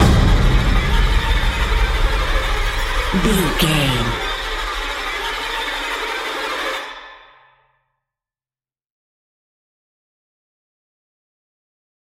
Scratching Violin Hit.
Atonal
scary
tension
ominous
disturbing
eerie
drums
strings